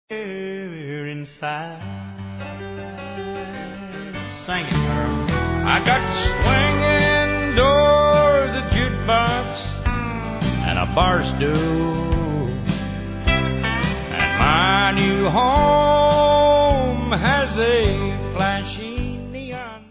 Incl. duet w